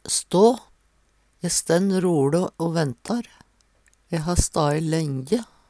stå - Numedalsmål (en-US)
DIALEKTORD PÅ NORMERT NORSK stå stå Infinitiv Presens Preteritum Perfektum stå stenn sto stae Eksempel på bruk E stenn roLe o ventar.